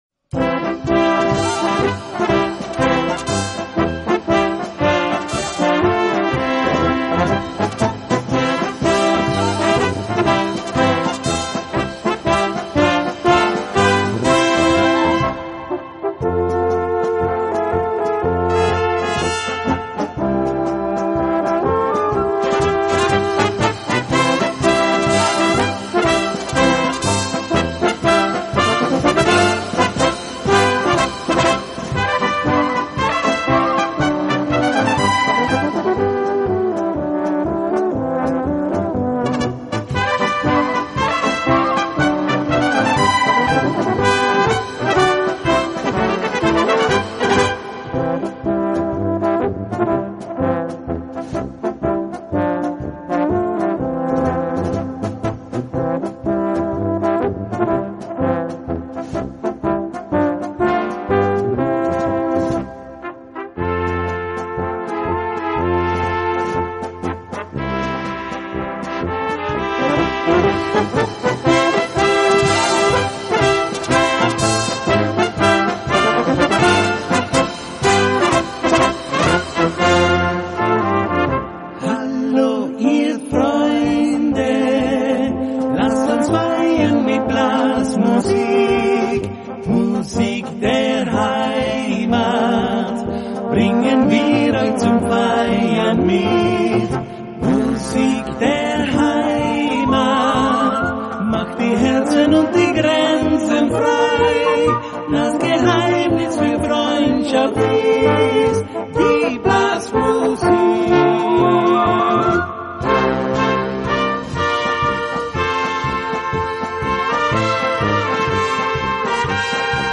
Gattung: für Kleine Blasmusk
Besetzung: Kleine Blasmusik-Besetzung